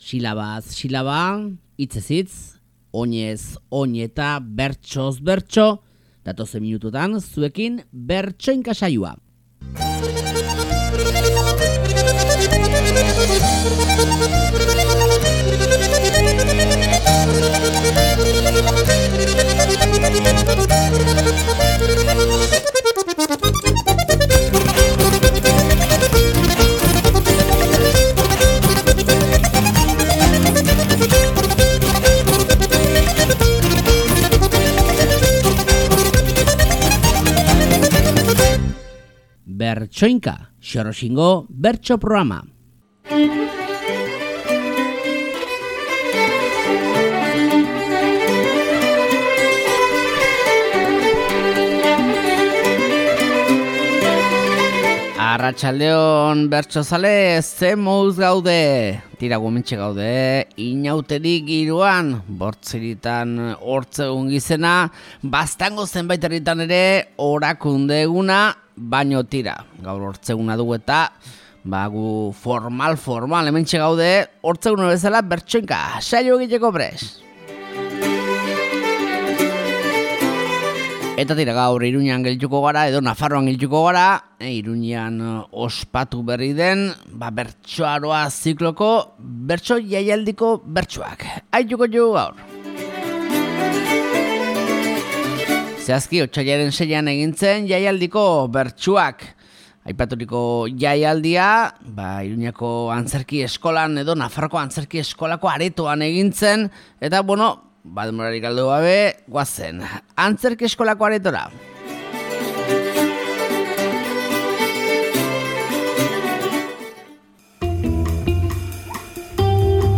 Joan den otsailaren 6an, Bertsoaroa zikloaren harira egindako bertso jaialdiko bertsoak aste honetako Bertsoinka saioan